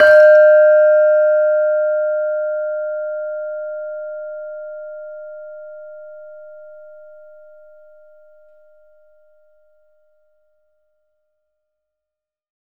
LAMEL D4  -L.wav